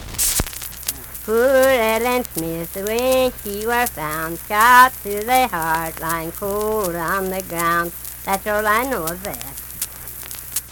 Unaccompanied vocal music
Voice (sung)
Logan County (W. Va.), Lundale (W. Va.)